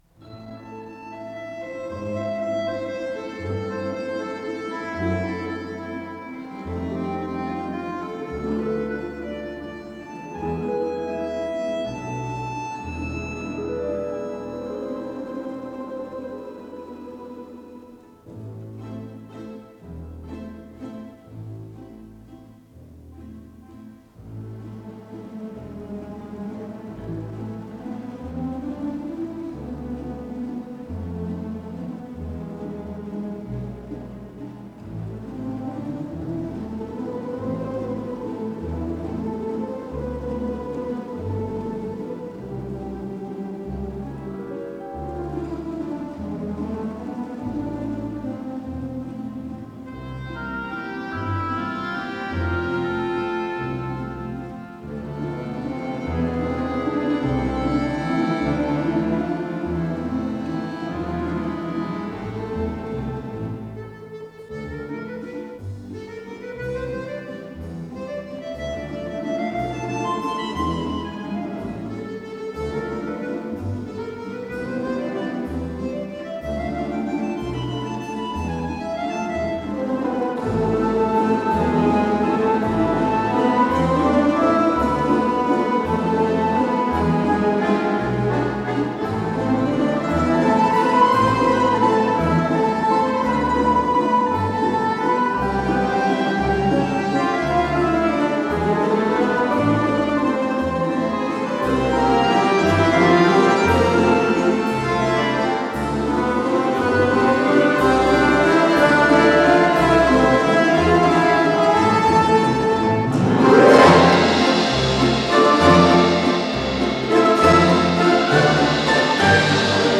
ПодзаголовокЛя минор
ВариантДубль моно